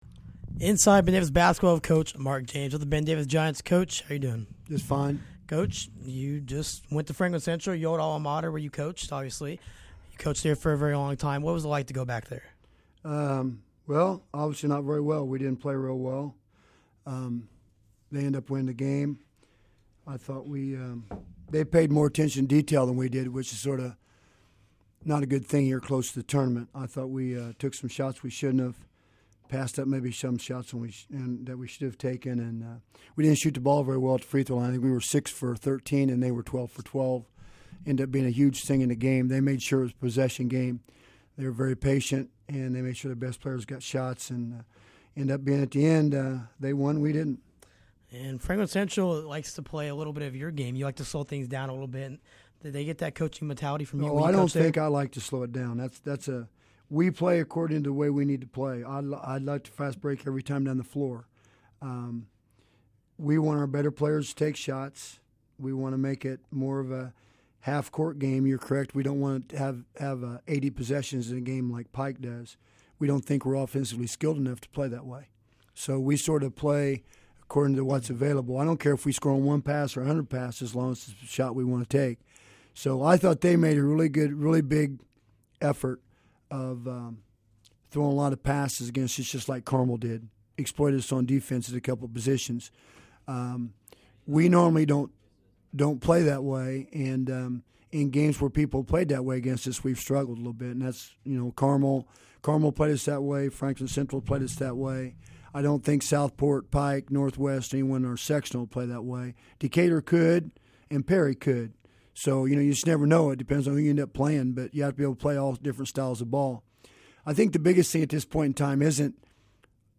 Weekly Talk